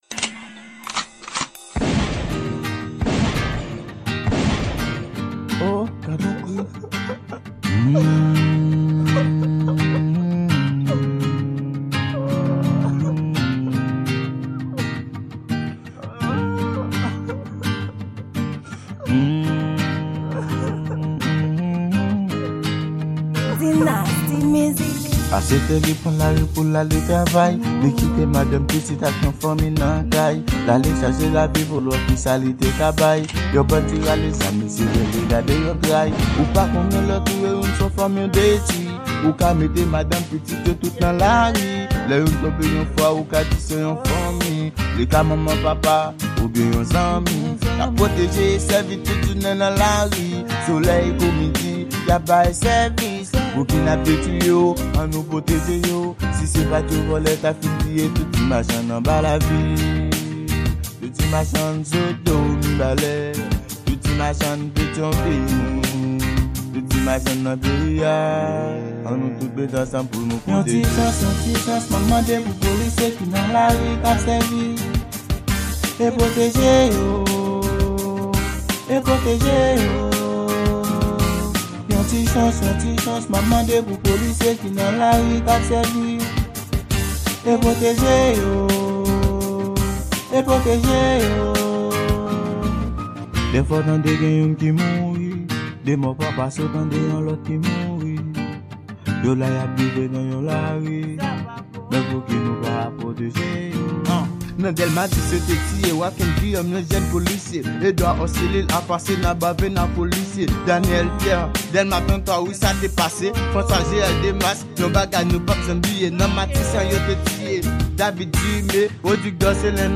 Genre: R&B.